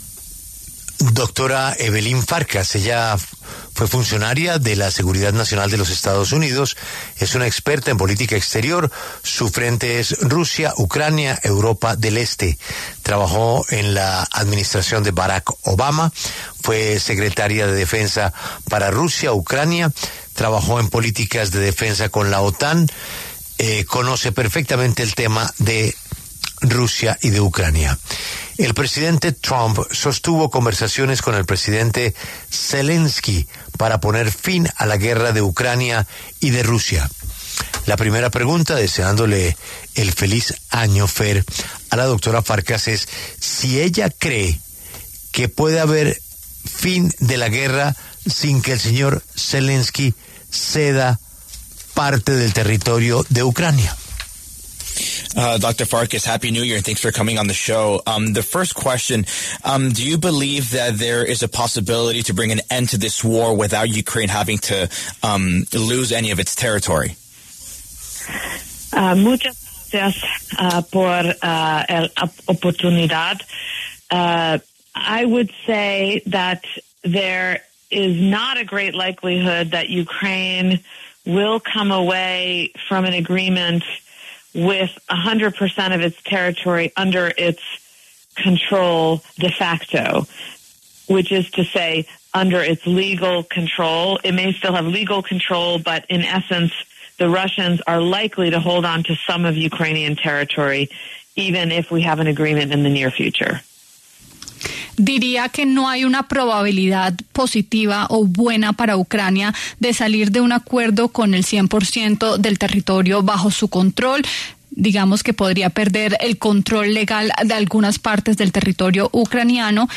La W habló con una experta en política exterior, quien resaltó que la probabilidad no es buena para Ucrania de salir del conflicto sin perder territorio.
La Dra. Evelyn Farkas, exfuncionaria de seguridad nacional de Estados Unidos y experta en política exterior, dialogó con La W acerca del posible fin del conflicto en Europa entre Ucrania y Rusia.